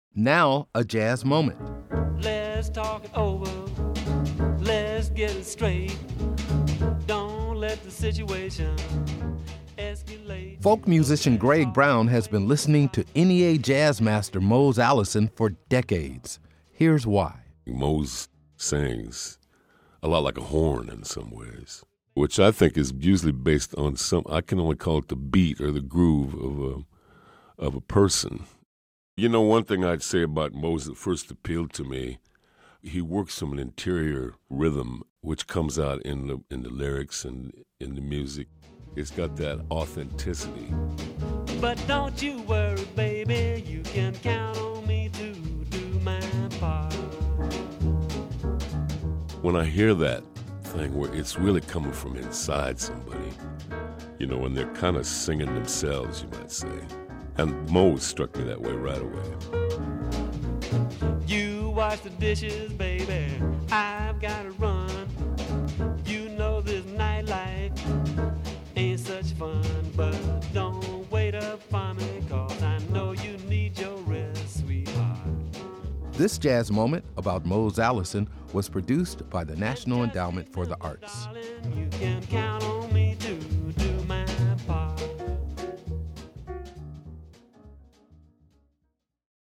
Music Credit: Excerpt of "You Can Count On Me To Do My Part" written and performed by Mose Allison from his cd, Allison Wonderland, used courtesy of Atlantic Records/Rhino, and used by permission of Audre Mae Music Inc. (BMI).